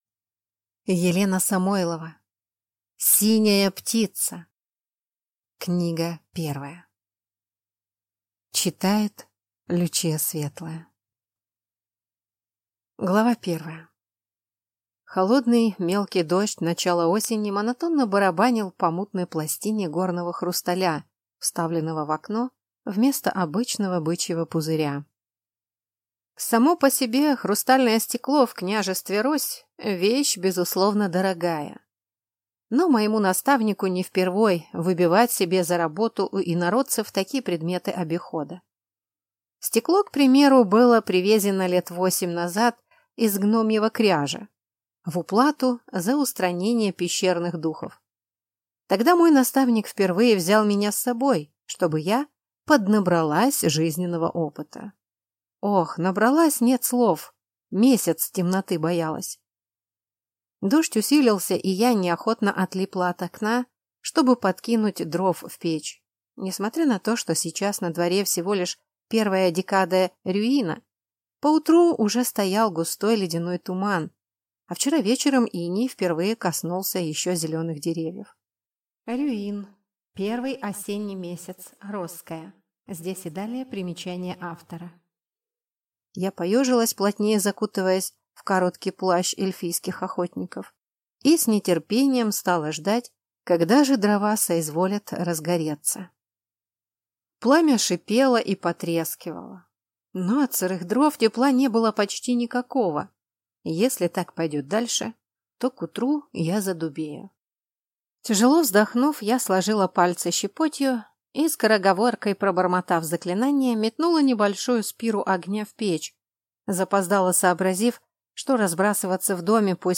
Аудиокнига Синяя Птица | Библиотека аудиокниг
Прослушать и бесплатно скачать фрагмент аудиокниги